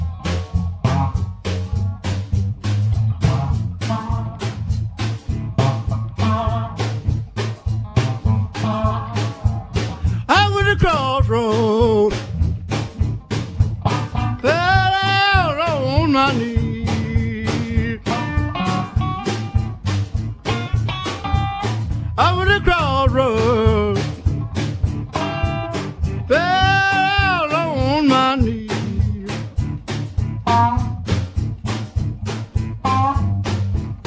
guitar/vocals
drums